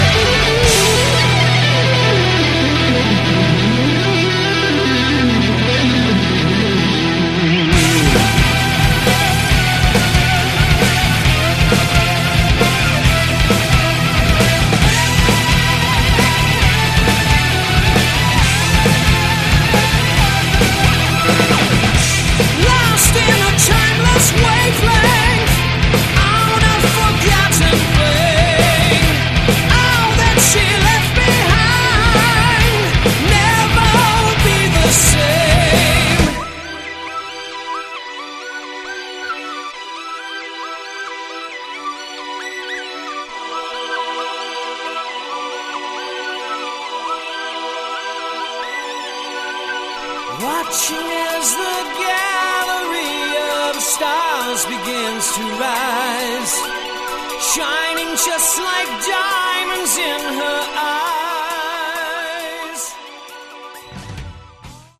Category: Melodic Metal
vocals
keyboards
drums